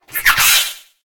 Cri de Grimalin dans Pokémon HOME.